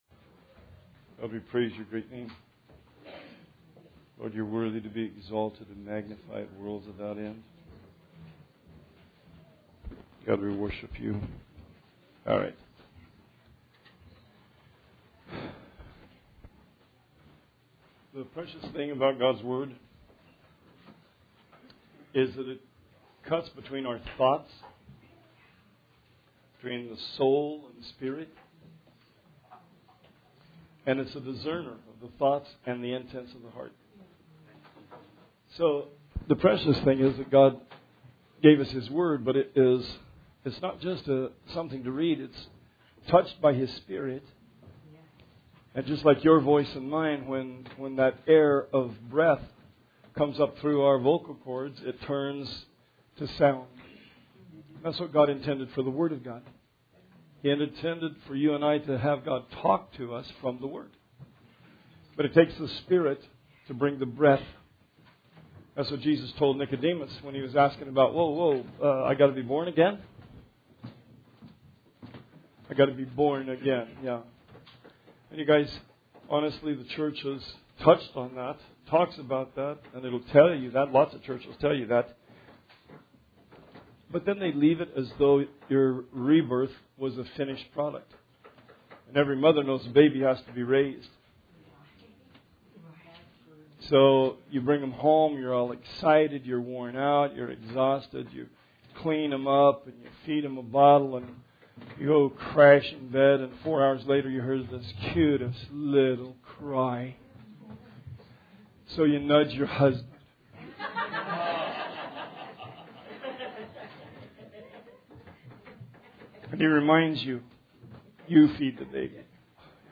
Sermon 9/22/19